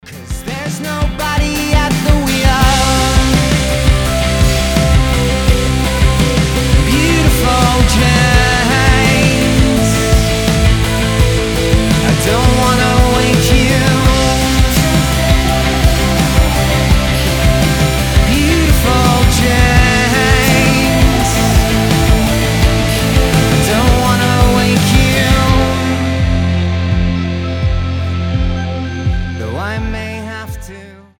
• Качество: 320, Stereo
атмосферные
красивый мужской голос
Alternative Rock